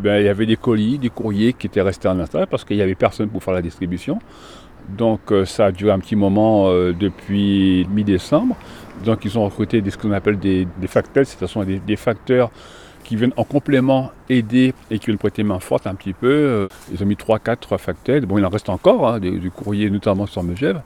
Et au début du mois de janvier 2023, certains n’avaient encore toujours pas reçu leur colis explique ce facteur titulaire de Sallanches.